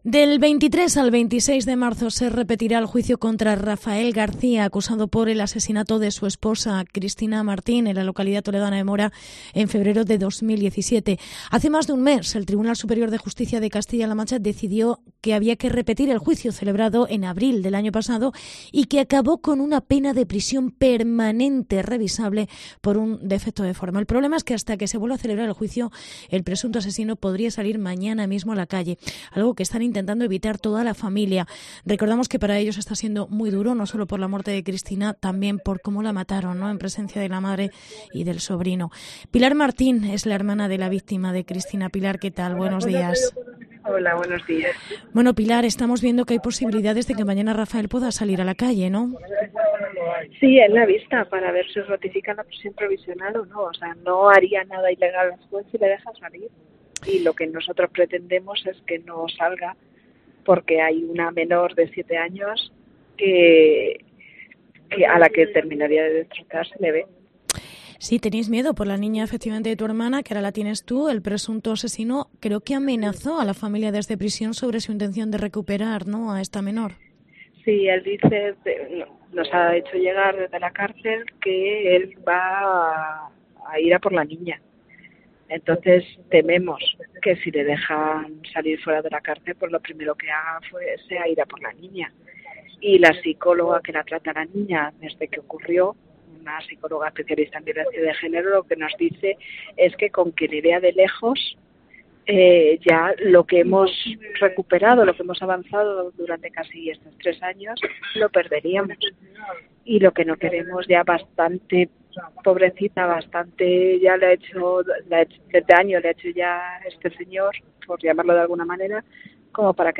en una concentración en Mora